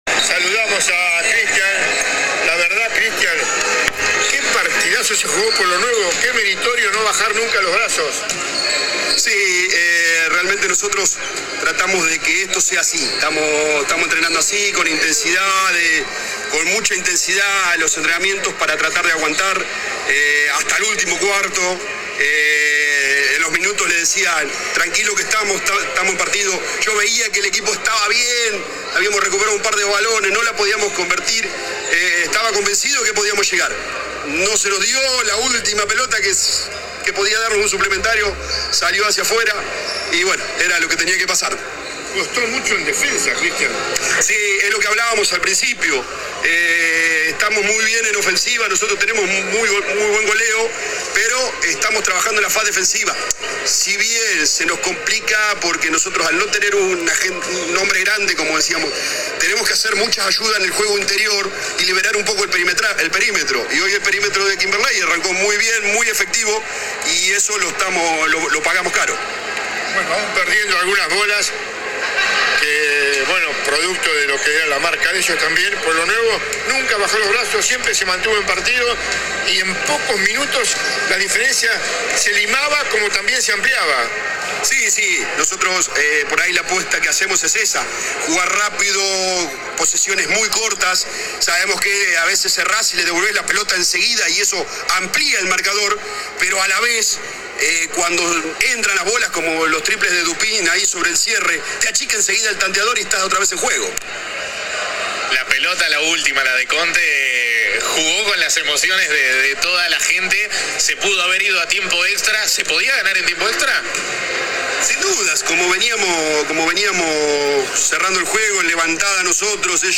Finalizado el partido dialogó para la transmisión de streaming que se realizó en vivo de las alternativas del partido.
AUDIO DE LA ENTREVISTA